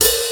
• Vintage Damped Fusion Open Hat A# Key 03.wav
Royality free open hat sound tuned to the A# note. Loudest frequency: 6926Hz
vintage-damped-fusion-open-hat-a-sharp-key-03-qyy.wav